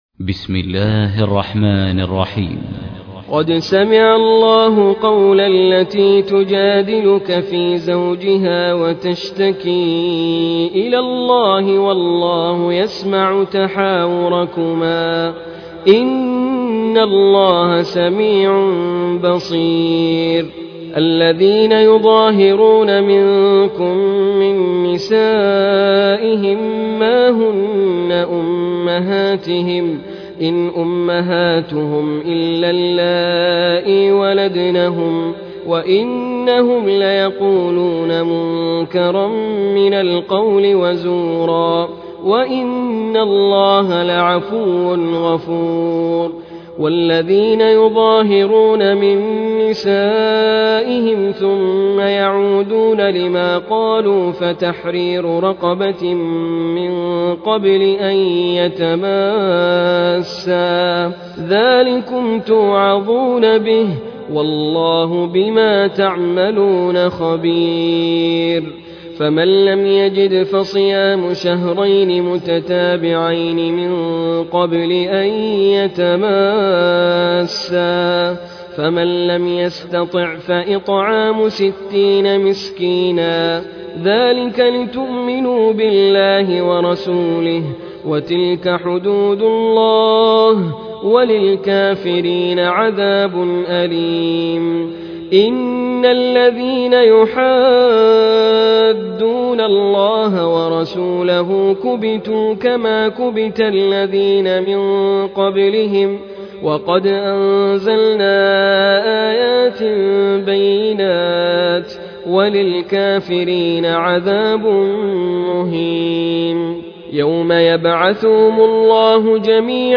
المصحف المرتل - حفص عن عاصم